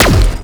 Zapper_3p_03.wav